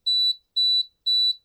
Bomb Timer.wav